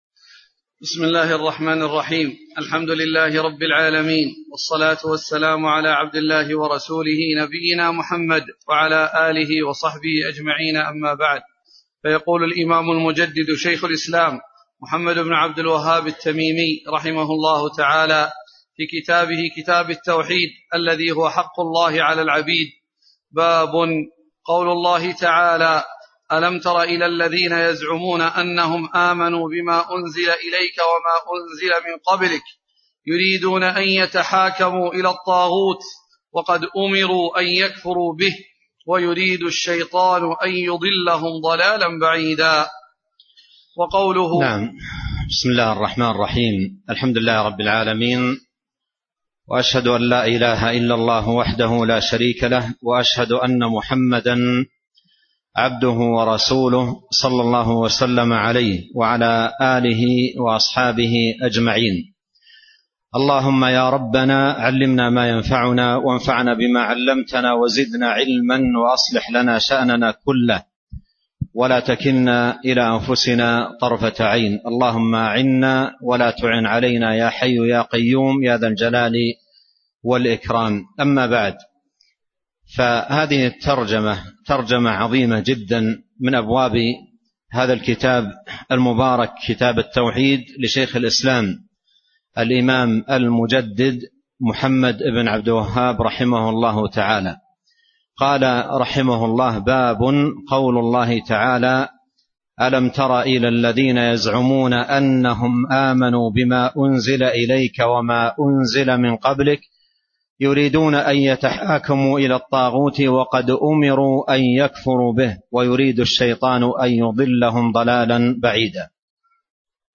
شرح كتاب التوحيد الذي هو حق الله على العبيد الدرس 40 باب قول الله تعالى: أَلَمْ تَرَ إِلَى الَّذِينَ يَزْعُمُونَ أَنَّهُمْ آمَنُوا...